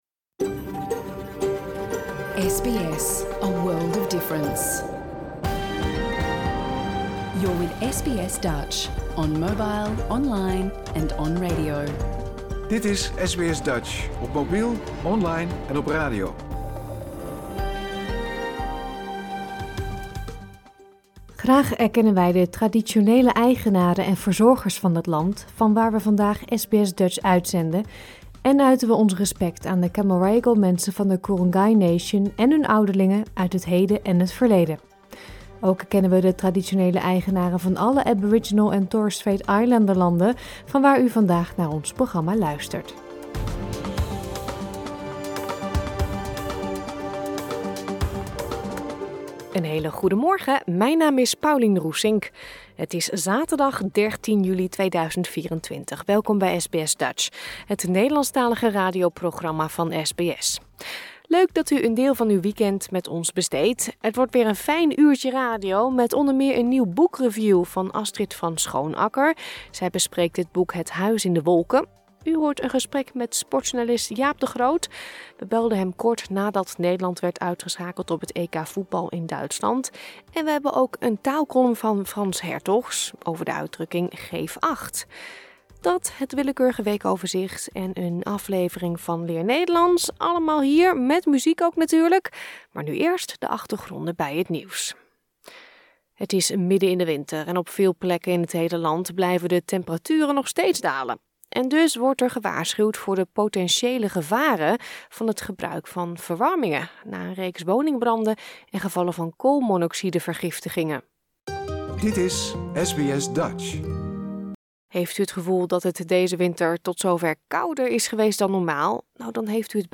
Luister hier de uitzending van zaterdag 13 juli 2024 (bijna) integraal terug.